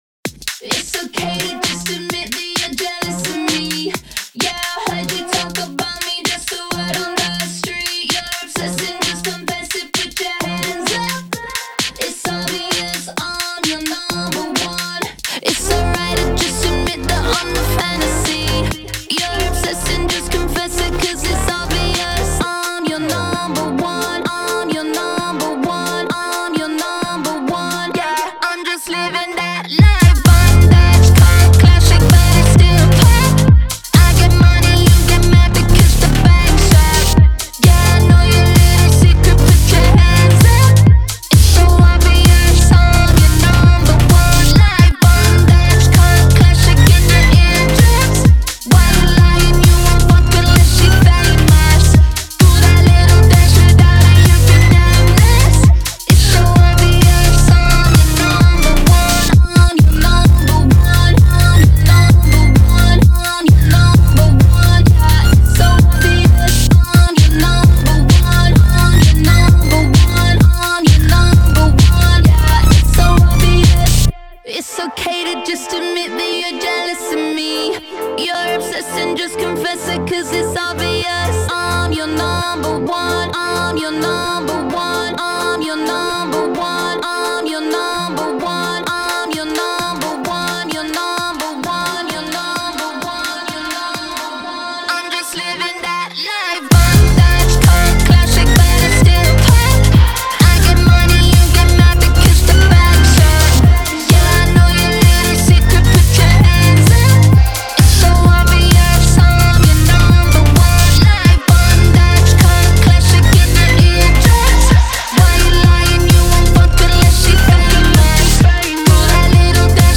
BPM130-130
Audio QualityPerfect (High Quality)
Hyperpop song for StepMania, ITGmania, Project Outfox
Full Length Song (not arcade length cut)